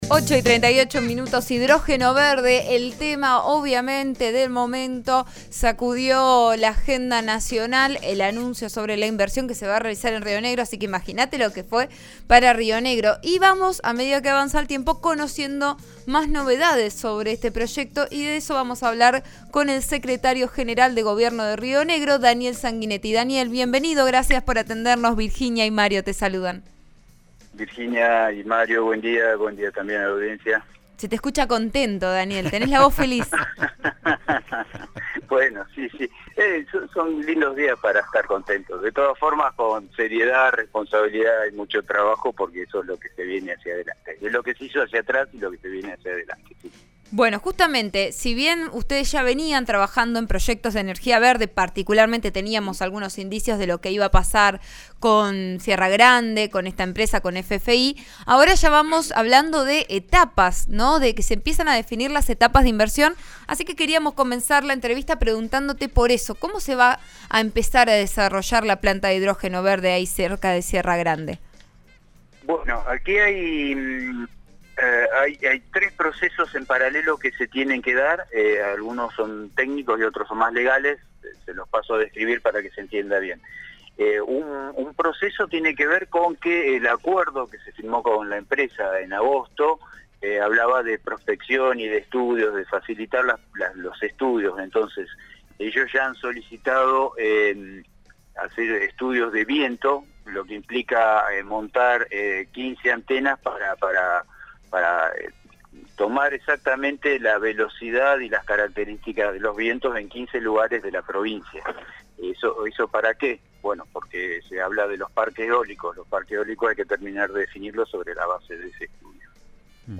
El secretario general de Gobierno, Sanguinetti, explicó en RN RADIO por qué confían en la firma que anunció una inversión de U$S 8.400 millones
Sobre este punto le preguntó «Vos A Diario» (RN RADIO 89.3) al secretario general de Gobierno de Río Negro, Daniel Sanguinetti.